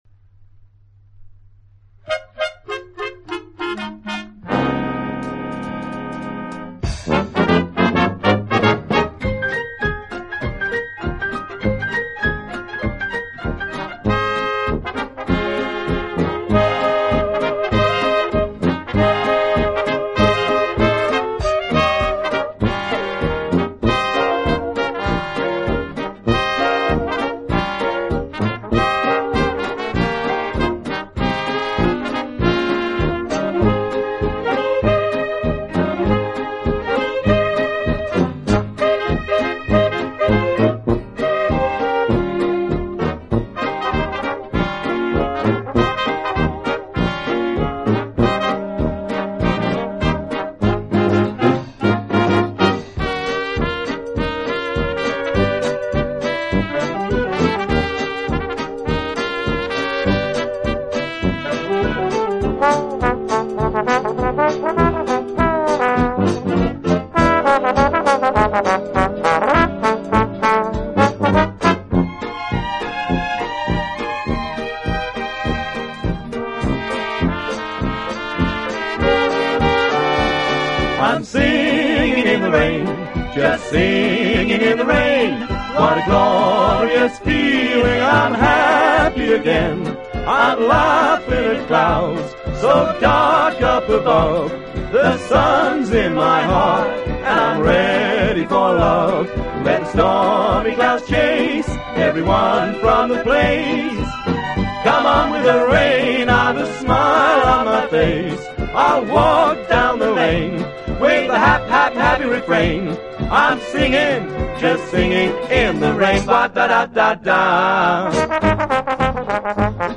轻音爵士
Swing爵士乐的曲调动听活泼、